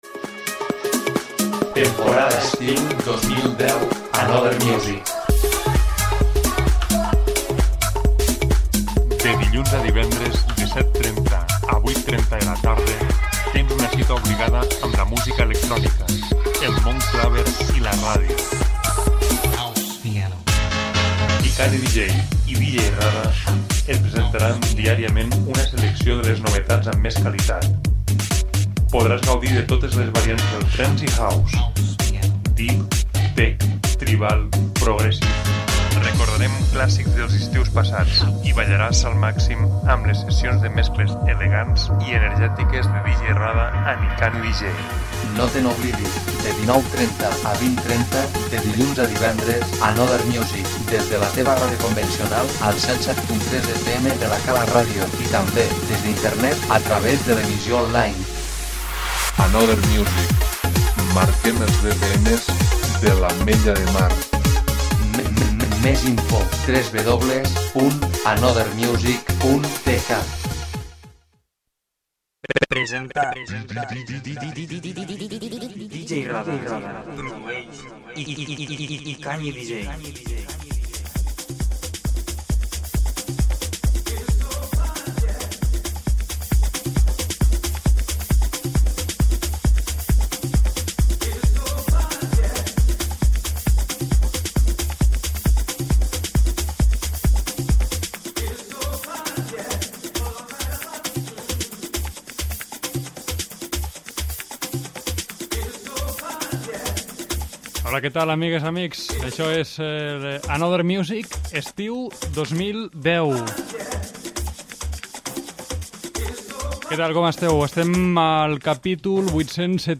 repas de novetats trance i house